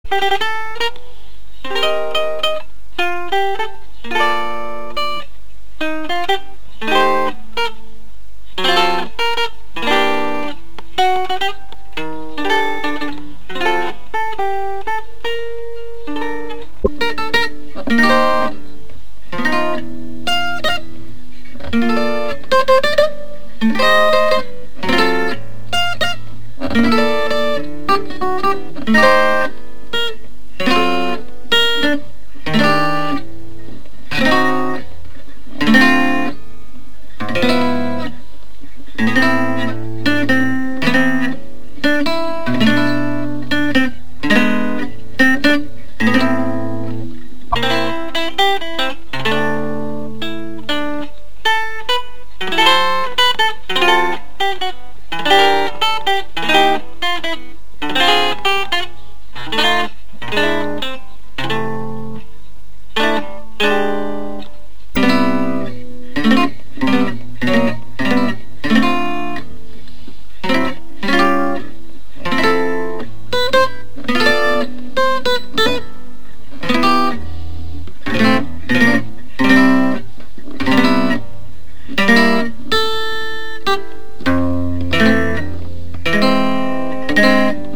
コメント: Chaki と Maruha No.303 の生音比較盤